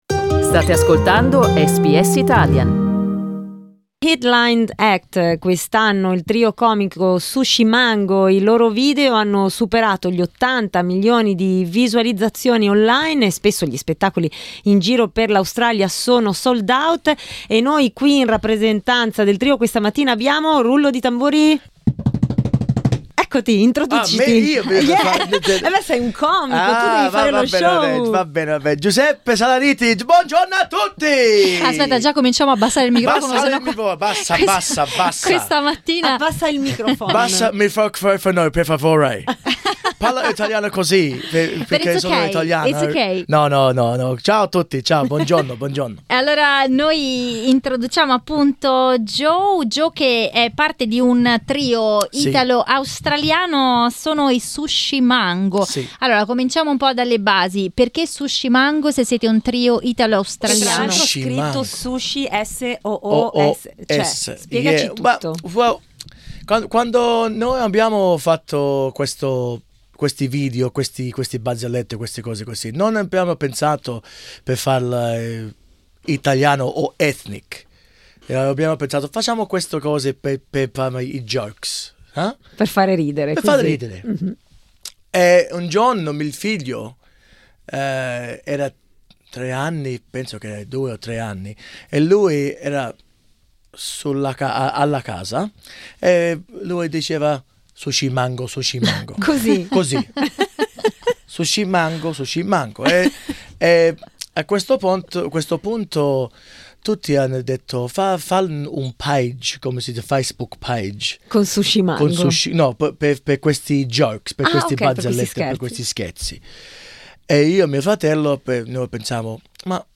Come è nato il nome "Sooshi Mango": ascolta l'intervista
Ospiti del programma di SBS Italian, hanno raccontato come è nato il loro nome.